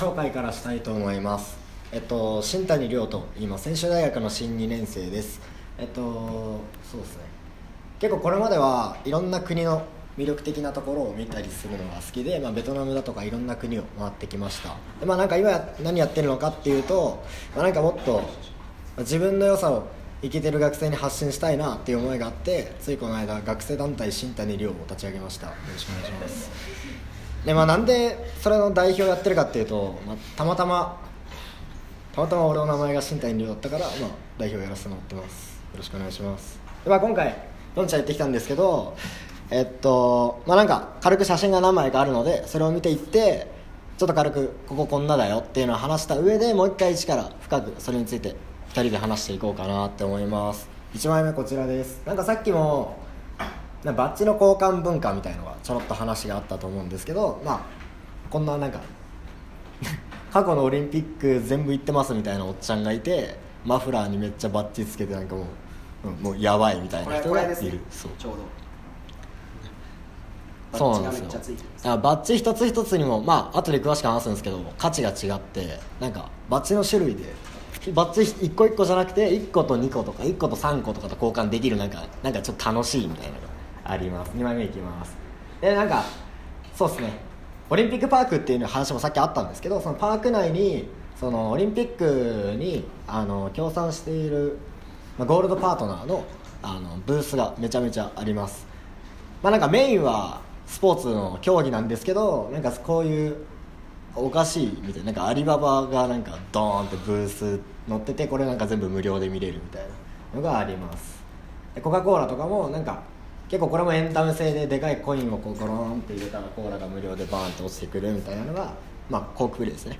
スポーツ関係者や、オリンピックビジネスに何やら関わっている素敵な人達50人くらいの前で報告する会があって 10分くらい後輩と喋っている音声です。